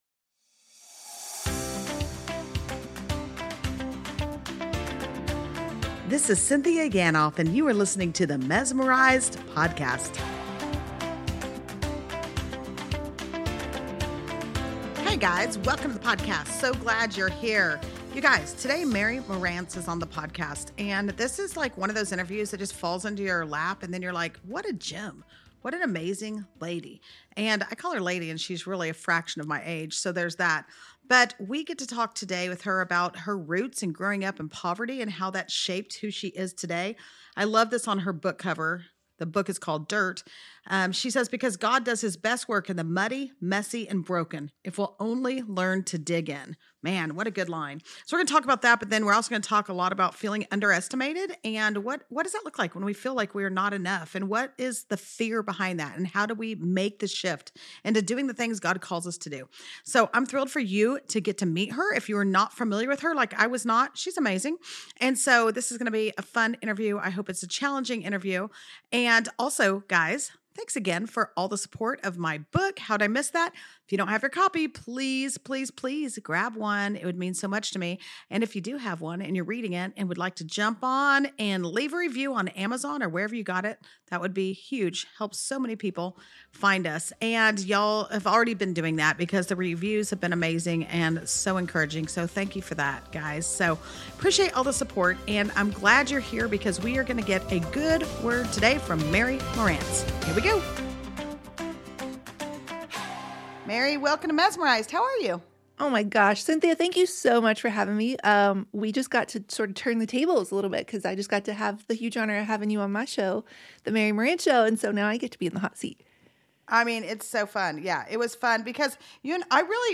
It’s a really honest conversation about comparison, calling, and showing up anyway—even when you don’t feel ready, qualified, or confident—trusting that God can use ordinary obedience more than perfect performance.